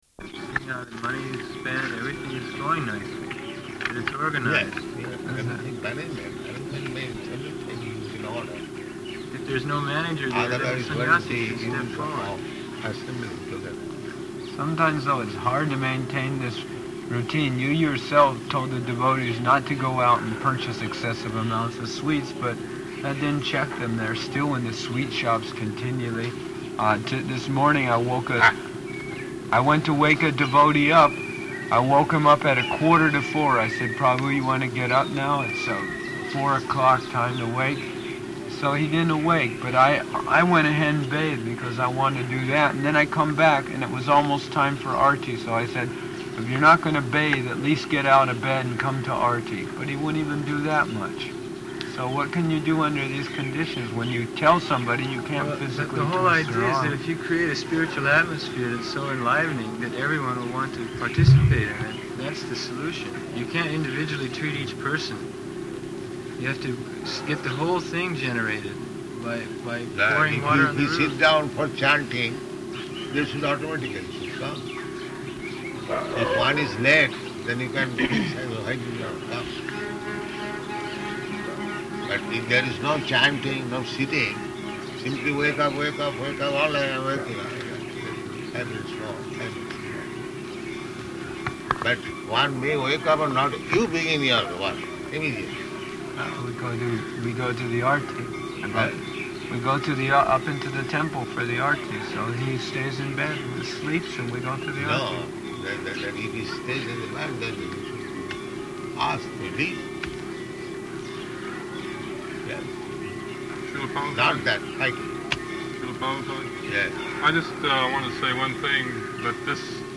Room Conversation
-- Type: Conversation Dated: April 5th 1976 Location: Vṛndāvana Audio file